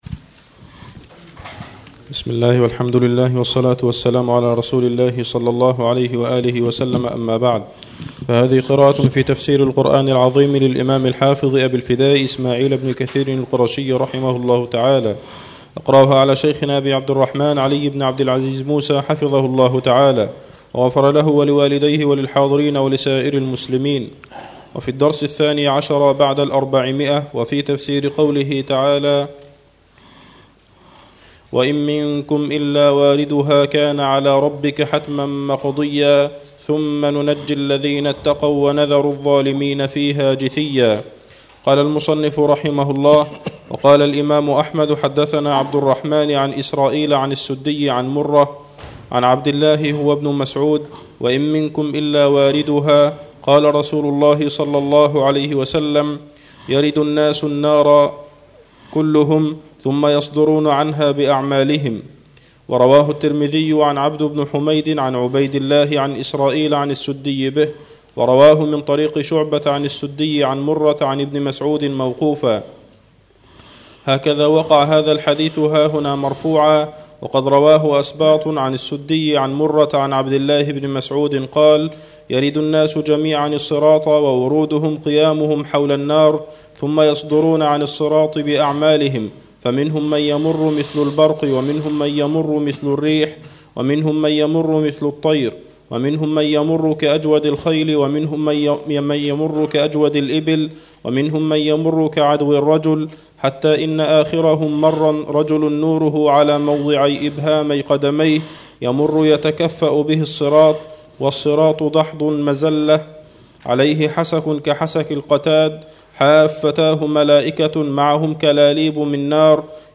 الدرس الثاني عشر بعد الأربعمئه